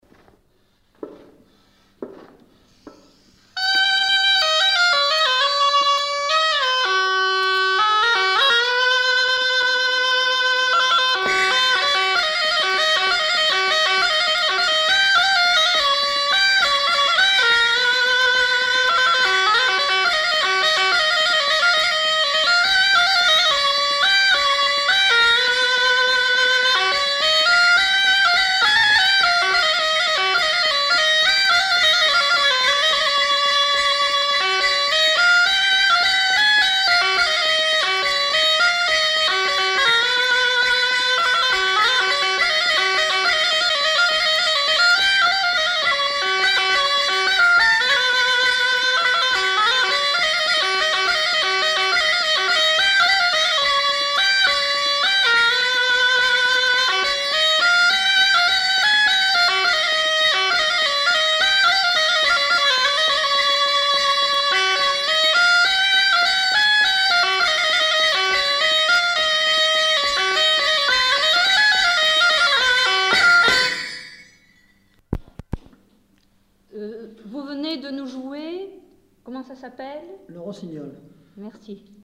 Lieu : Marvejols
Genre : morceau instrumental
Instrument de musique : cabrette ; grelot
Danse : valse